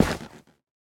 Minecraft Version Minecraft Version snapshot Latest Release | Latest Snapshot snapshot / assets / minecraft / sounds / block / nylium / step1.ogg Compare With Compare With Latest Release | Latest Snapshot
step1.ogg